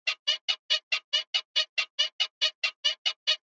91V_CT_140_fx_loop_bed_squeak_grail_drop
bedsqueak.mp3